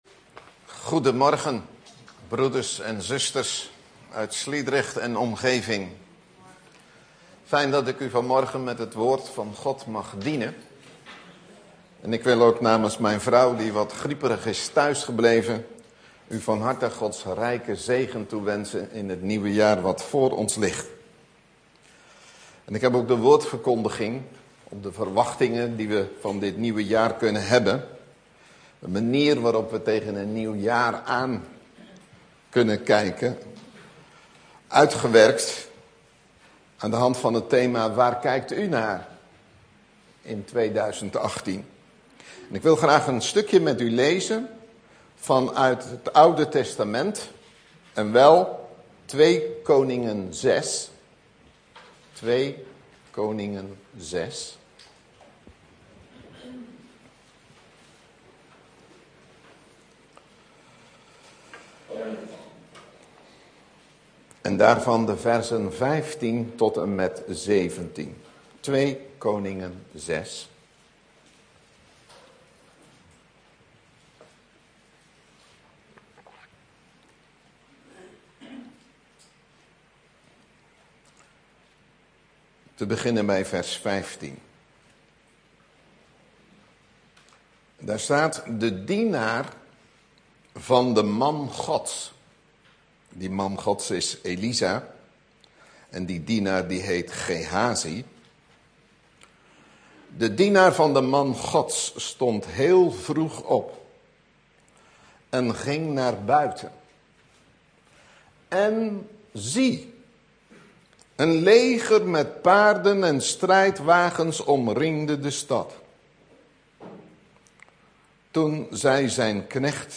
In de preek aangehaalde bijbelteksten (Statenvertaling)2 Koningen 6:15-1715 En de dienaar van den man Gods stond zeer vroeg op, en ging uit; en ziet, een heir omringde de stad met paarden en wagenen.